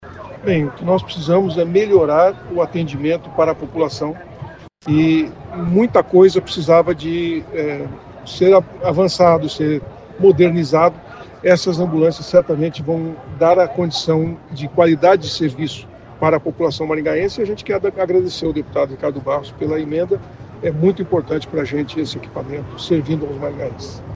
Ouça o que diz o prefeito Silvio Barros: